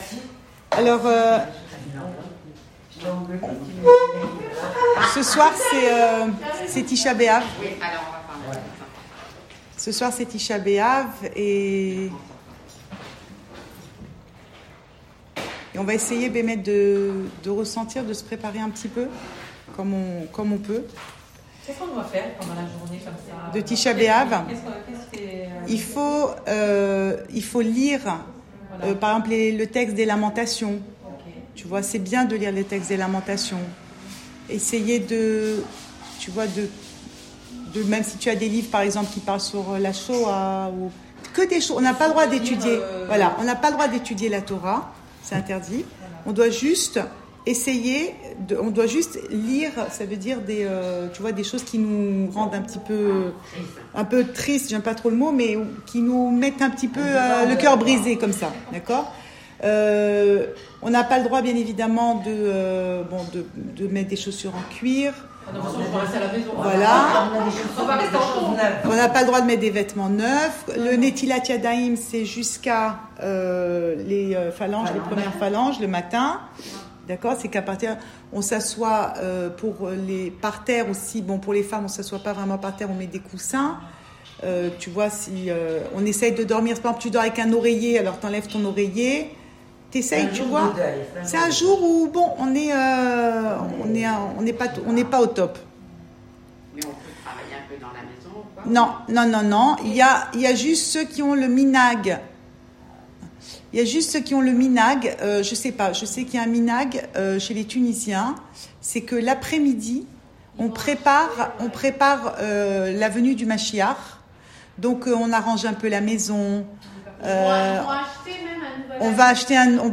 Cours audio Fêtes Le coin des femmes Pensée Breslev - 29 juillet 2020 29 juillet 2020 Ticha Béav : on a envie de retrouver Hachem ! Enregistré à Tel Aviv